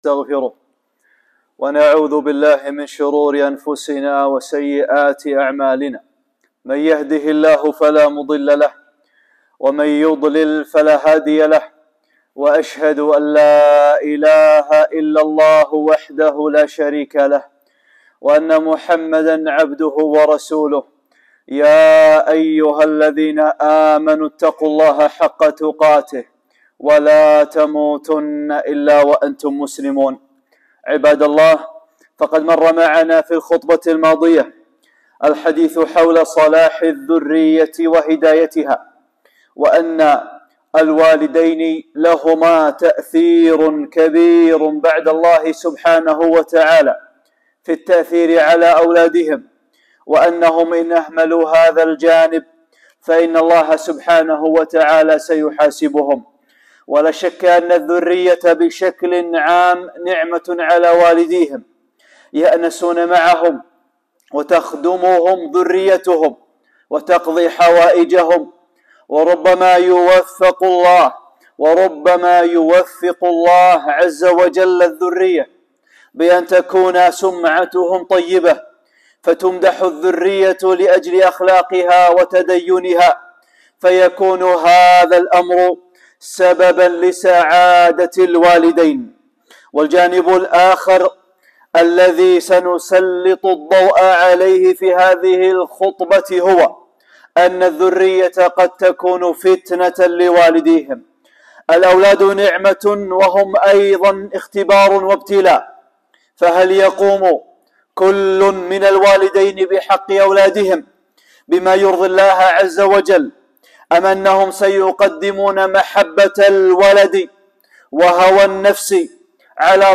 (7) خطبة - أولادكم فتنة